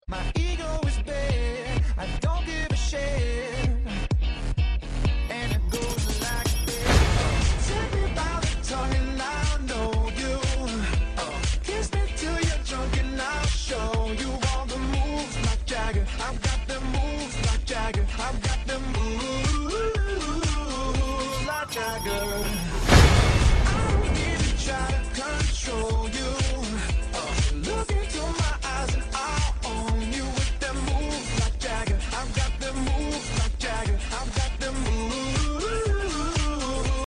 i included 2 beat drops sound effects free download